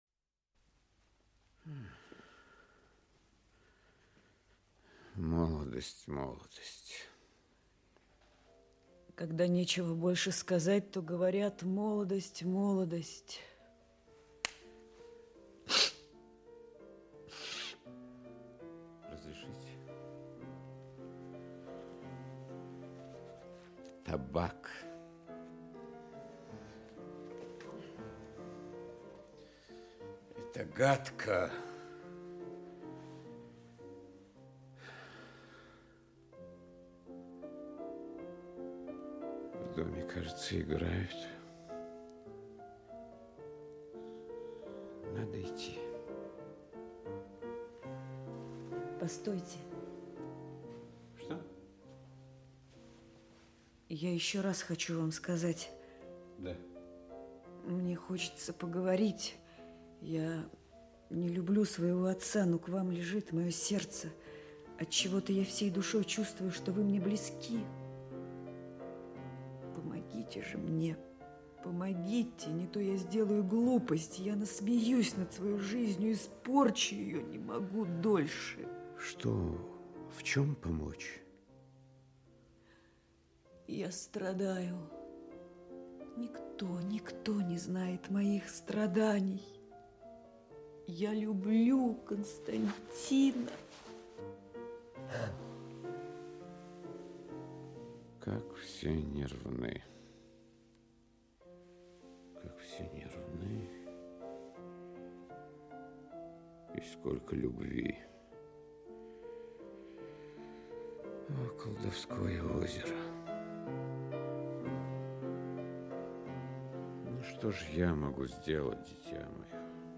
Спектакль МХАТа СССР им. М. Горького.